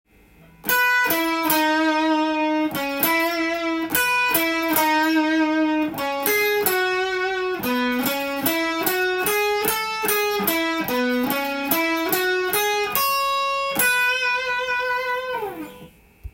イントロ　ギター用tab譜
譜面通りエレキギターで弾いてみました